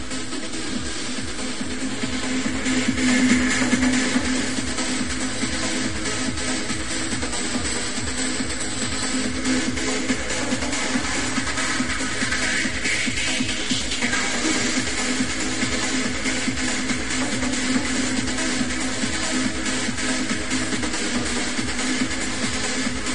sounds like traffic at the starting, just a bit lol